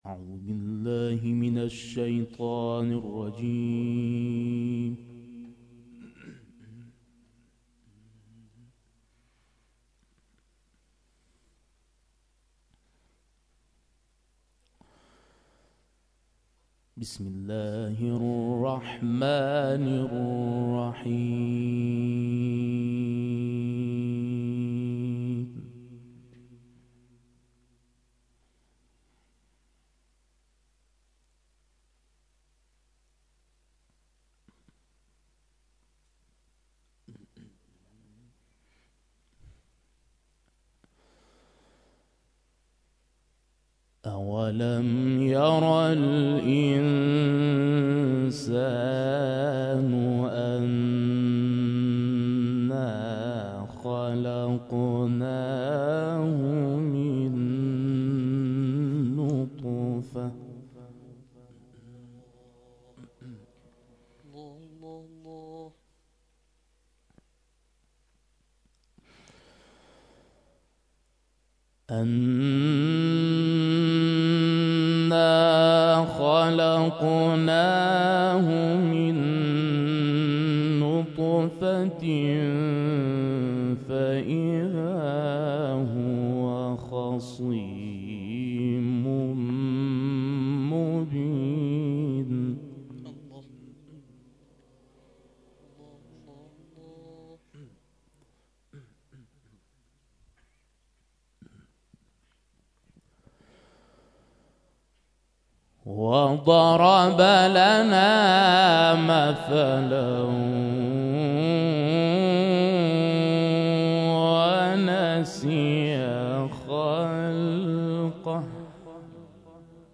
نخستین محفل انس با قرآن روستای زارگز از توابع شهرستان درمیان، به مناسبت هفته وحدت روز گذشته با حضور قاری بین‌المللی کشورمان برگزار شد.